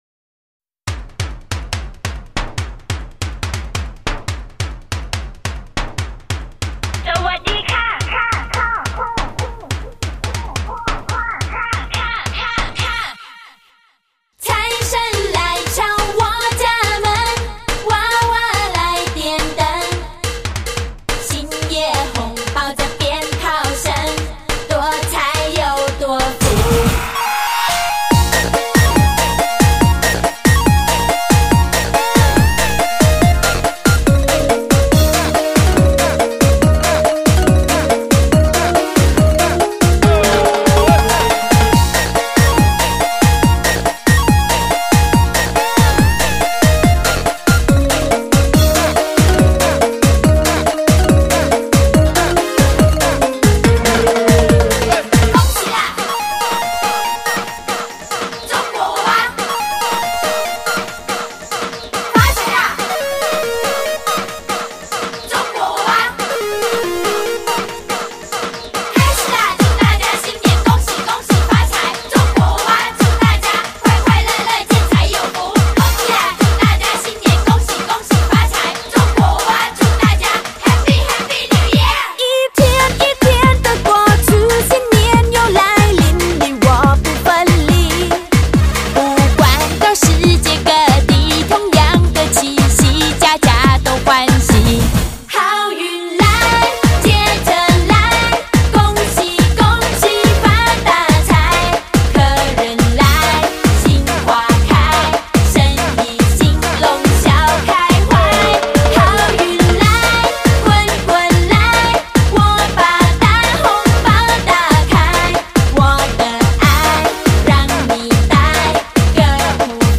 乐风格: 流行资源